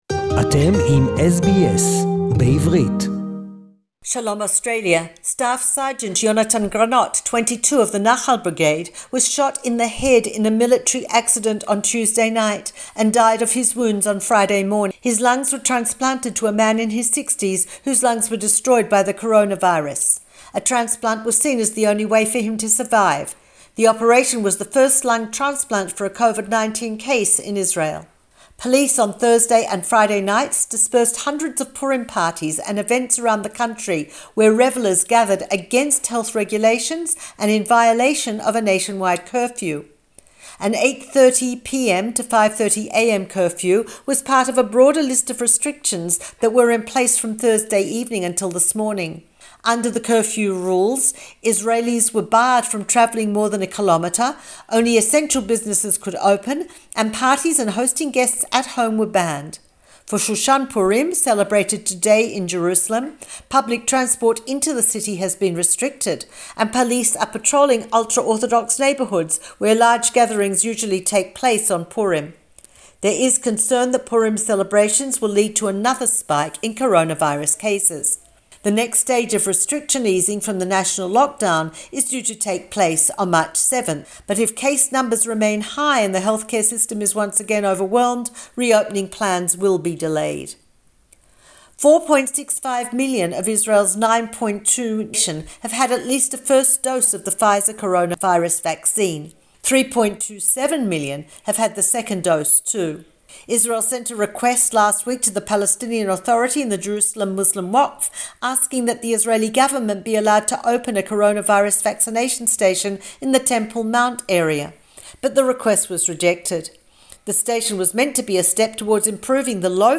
SBS Jerusalem weekly report....